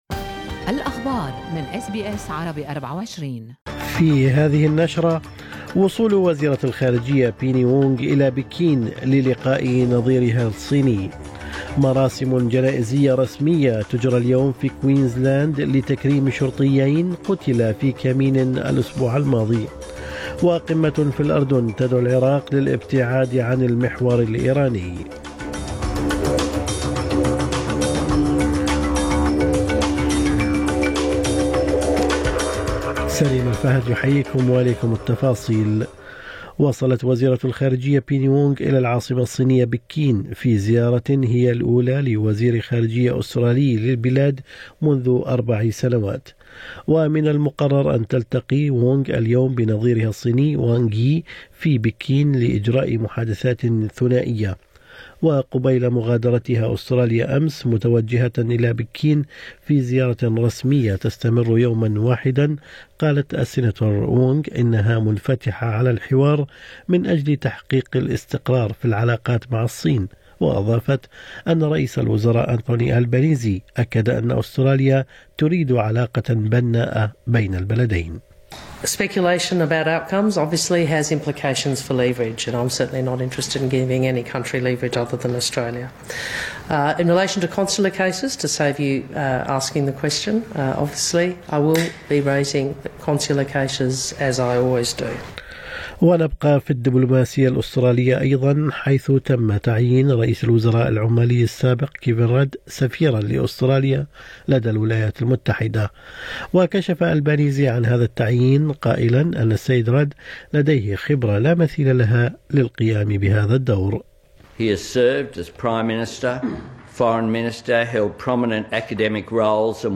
نشرة اخبار الصباح 21/12/2022